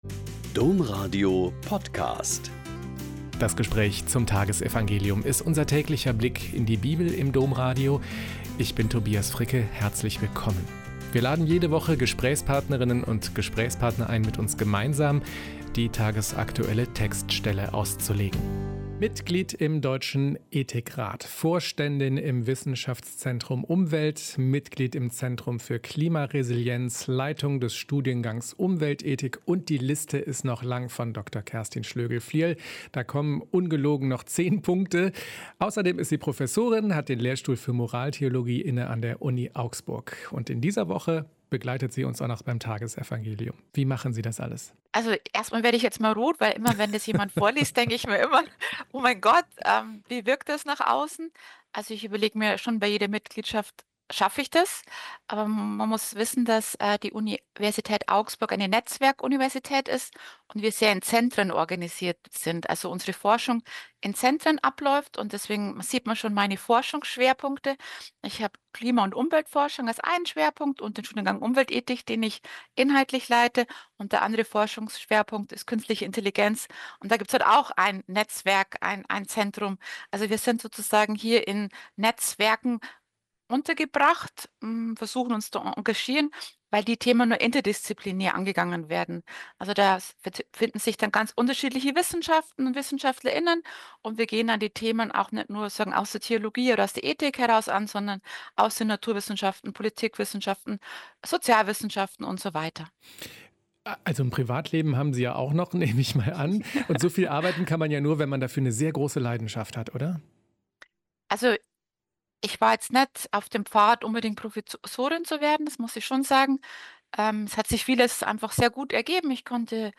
Mt 19,27-29 - Gespräch